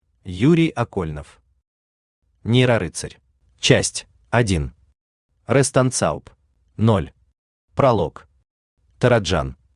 Аудиокнига Нейрорыцарь | Библиотека аудиокниг